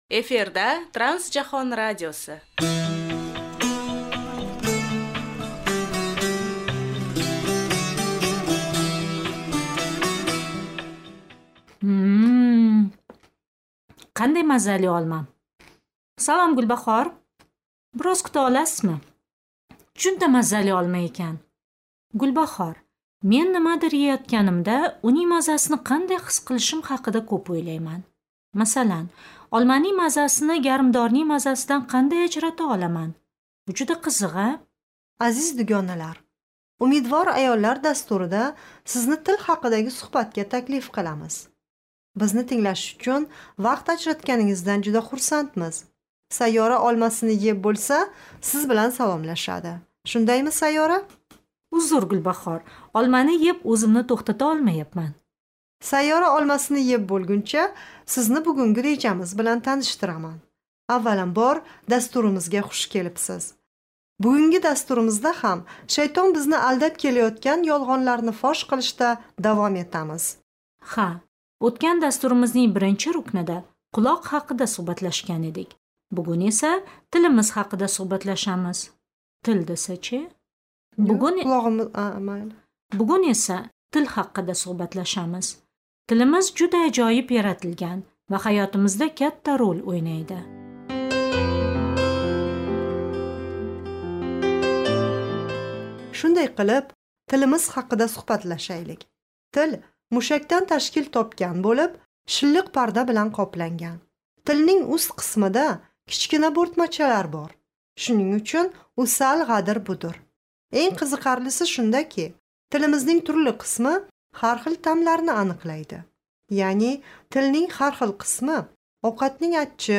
Varje avsnitt är 15 minuter långt och sänds på radio via mellanvåg en gång i veckan och går dessutom att lyssna till via webbplats, tjänsten Telegram och egen mobil-app.